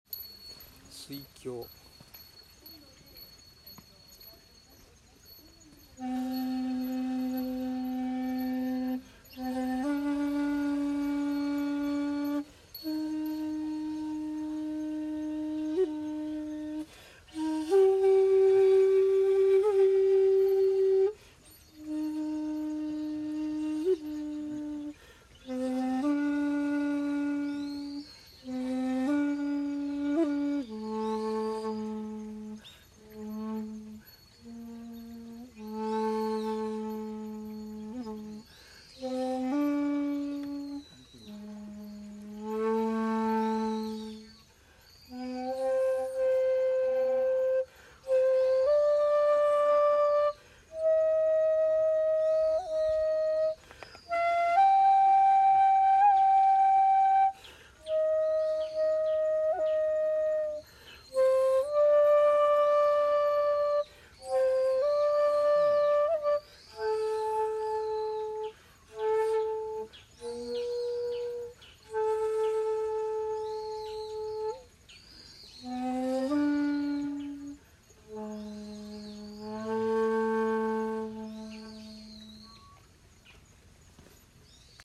私は国道をさらに東に歩き40番観自在寺に到着しました（14:35）。
いつものように参拝し、尺八を吹奏しました。
（写真⑤：観自在寺にて尺八）
◆◆　（尺八音源：観自在寺 「水鏡」）